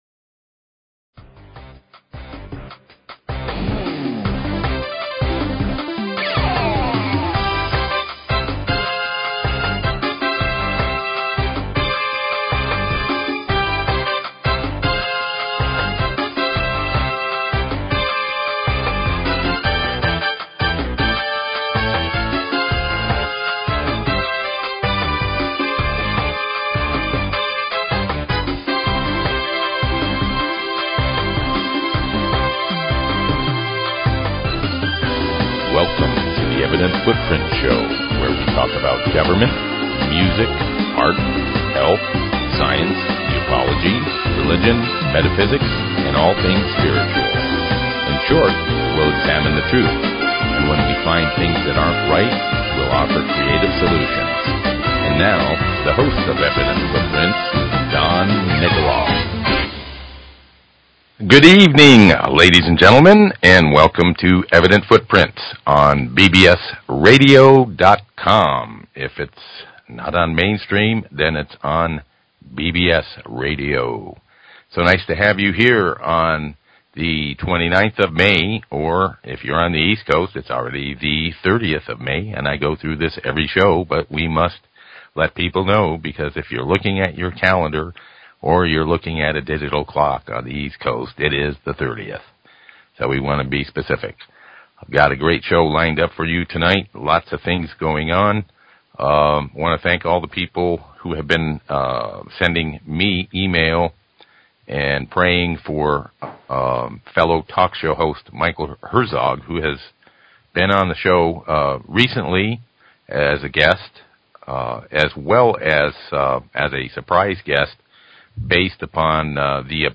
Talk Show Episode, Audio Podcast, Evident_Footprints and Courtesy of BBS Radio on , show guests , about , categorized as
Environmental Scientist, Health Consultant, Herbalist